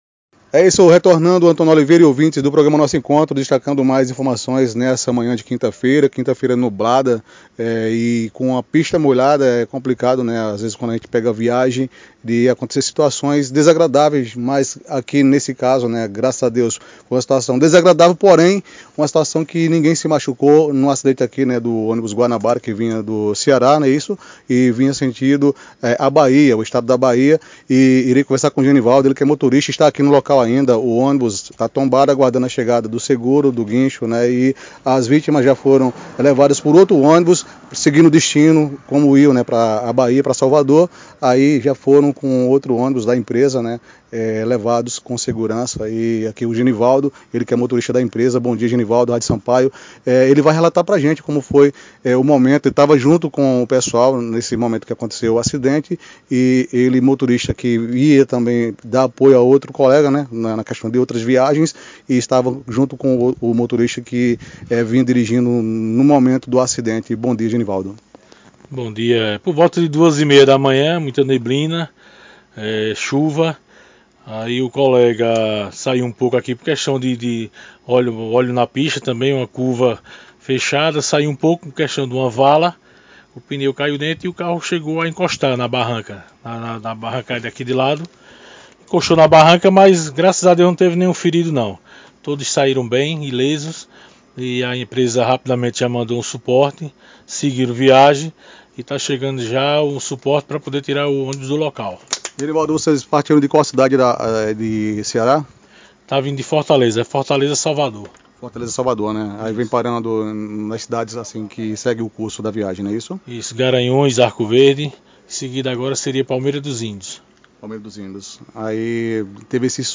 A seguir, ouça a entrevista completa, transmitida durante o programa Nosso Encontro, da Rádio Sampaio.